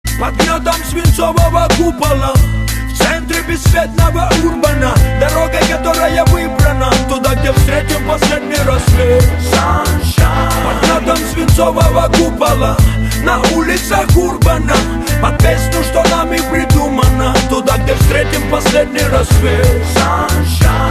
Главная » Файлы » Hip-Hop, RnB, Rap